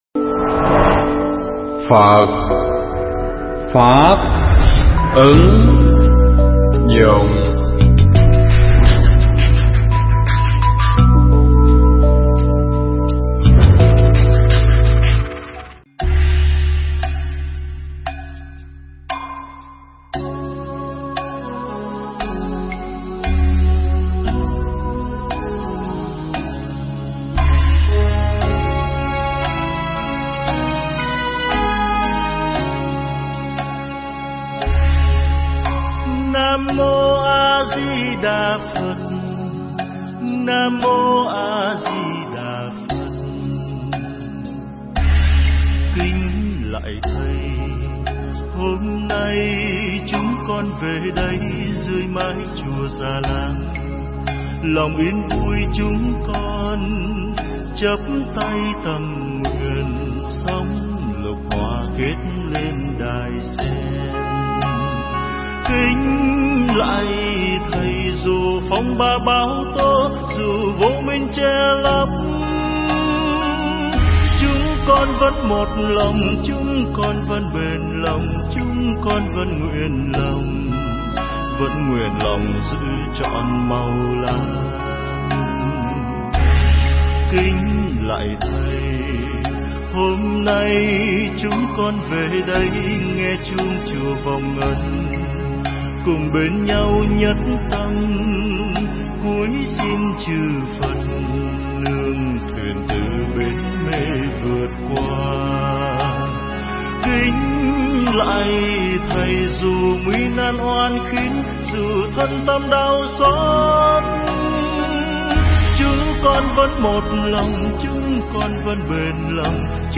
Nghe Mp3 thuyết pháp Bốn Điều Đắc Nhân Tâm
Mp3 pháp thoại Bốn Điều Đắc Nhân Tâm